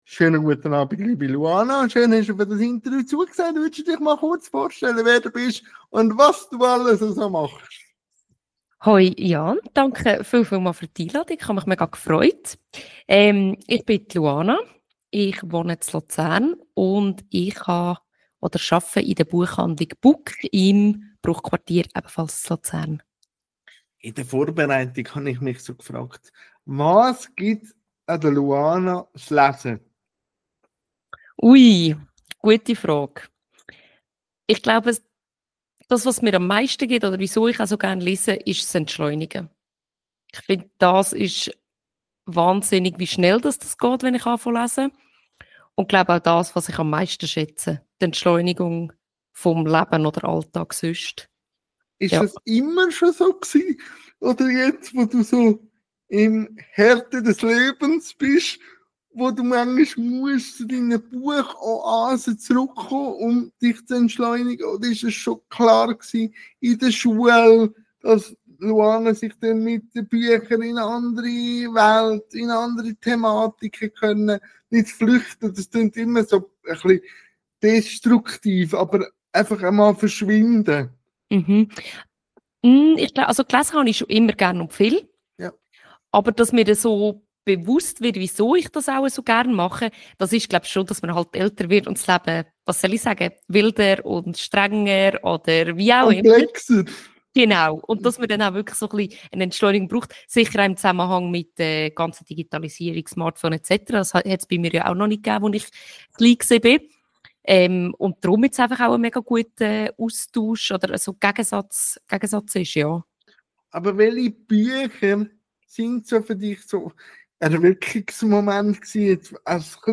INTERVIEW-THEMEN | Politik, Sport u. v. m